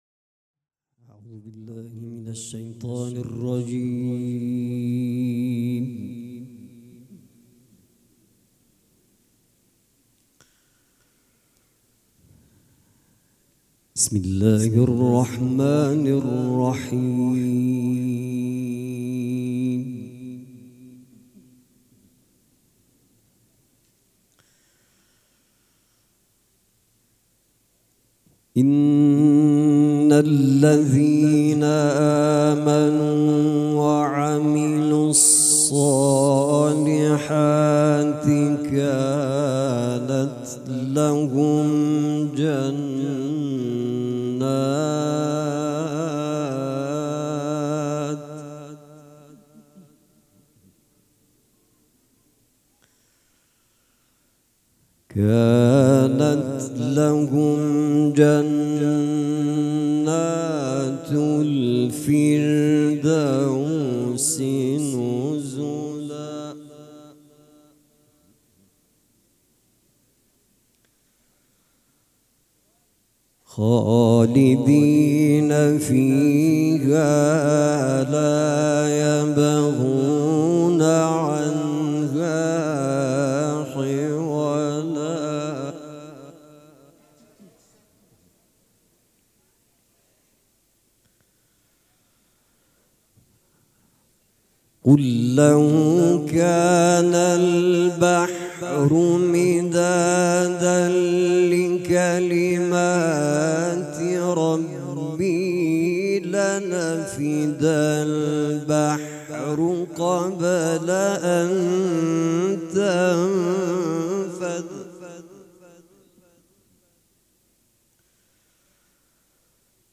قرائت قرآن کریم
شهادت امام صادق علیه السلام سه شنبه 27 خرداد ماه 1399 حسینیه ی ریحانه الحسین (س)